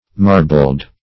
Marbled \Mar"bled\, a.